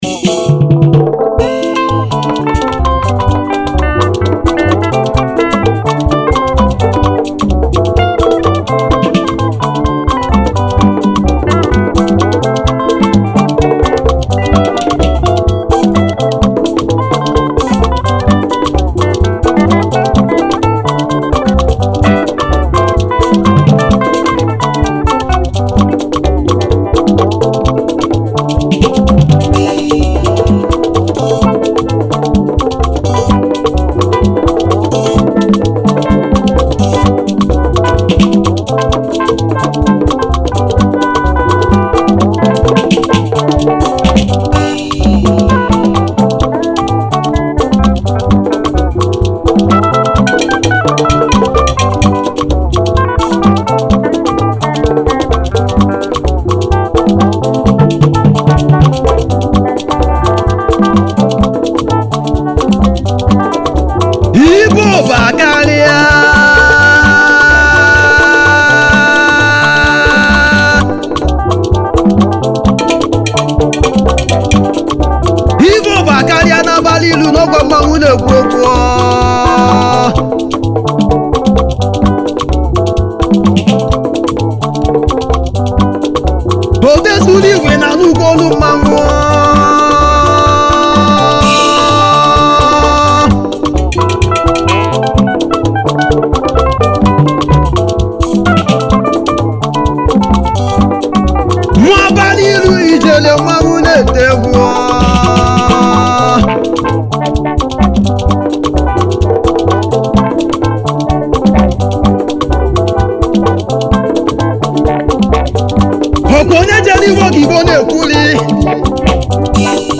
Highlife Traditional Free